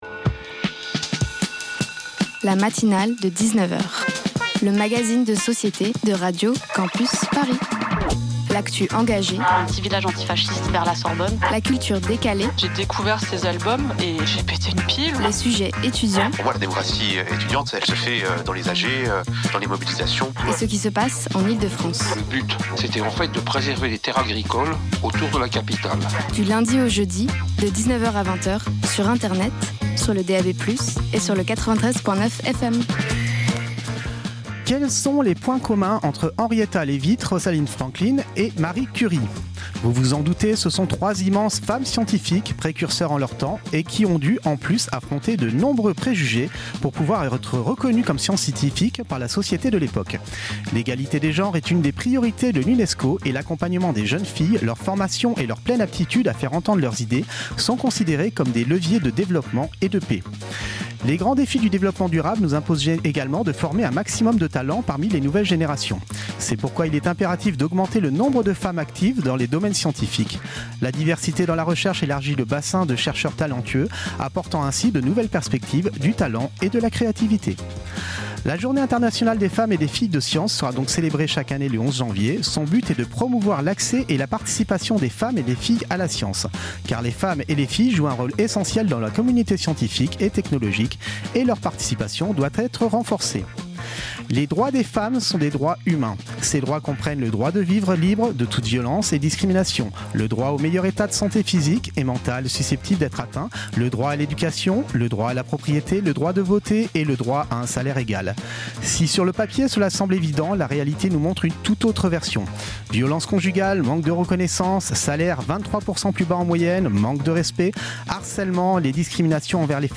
Procédures baillons contre la presse & Association des Femmes et des Droits Partager Type Magazine Société Culture mercredi 11 février 2026 Lire Pause Télécharger Ce soir